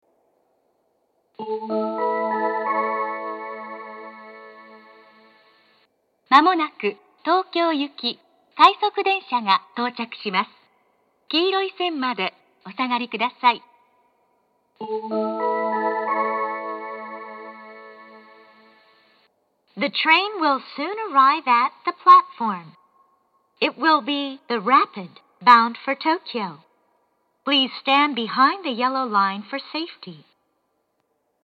音質が向上しています。
上り接近放送 快速東京行の放送です。